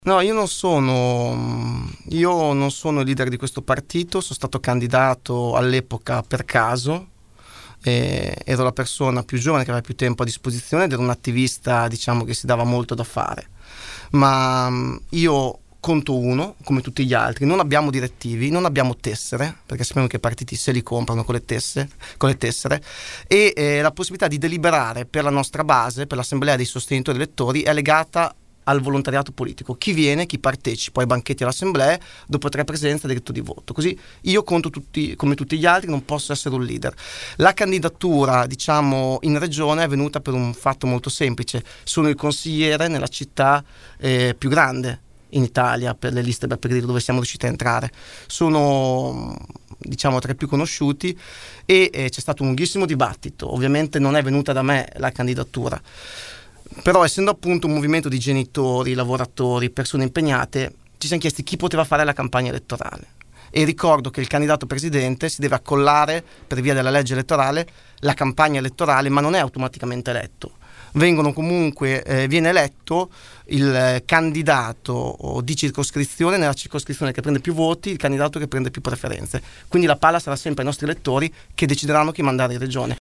Ecco una sintesi dell’intervista andata in onda questa mattina.